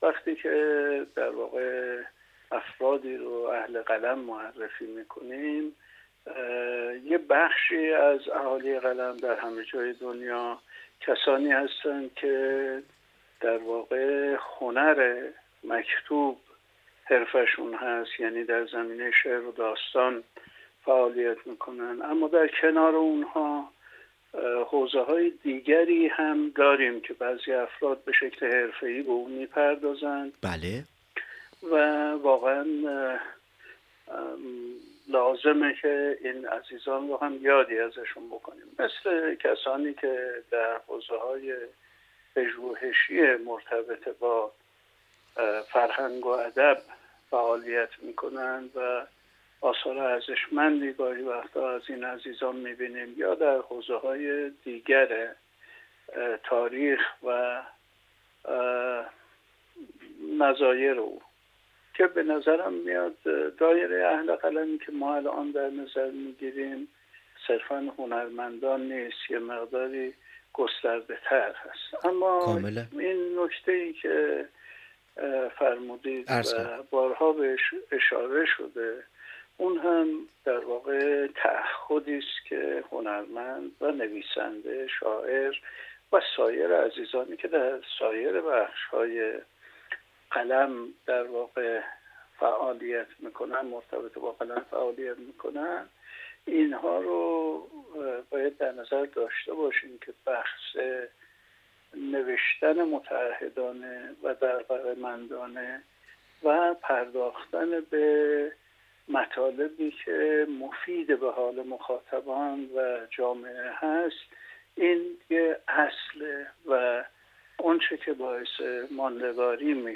بنای این گفت‌وگو روایتی صریح و بی‌پرده بود و در پاره‌ای از فرازهای این گفت‌وگو به‌ سبب زاویه‌هایی که گفتار دو مهمان این میزگرد داشتند، بحث‌های گرمی درگرفت که همراهی با این گفت‌وگو را برای هر مخاطبی جذاب‌تر می‌کند.